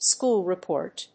アクセントschóol repòrt